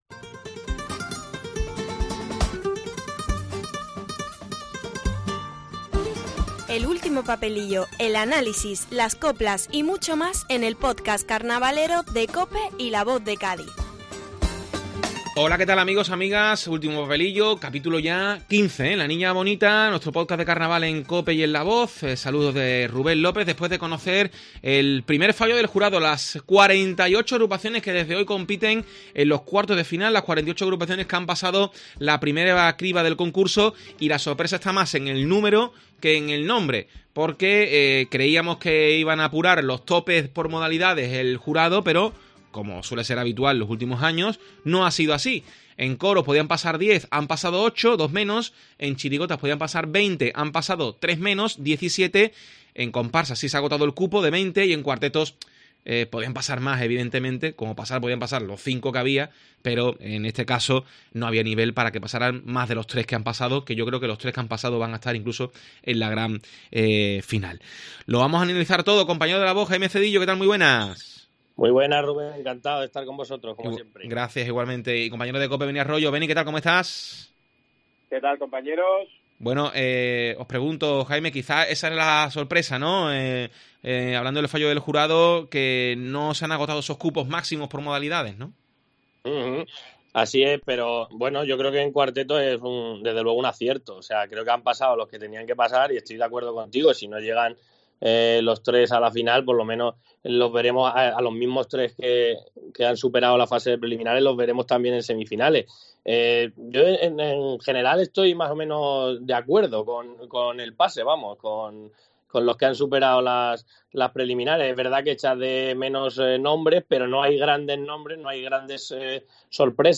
Escucha el podcast de Carnaval con el debate, las coplas y los protagonistas tras la última sesión de clasificatorias